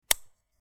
UI_Sound_Select.wav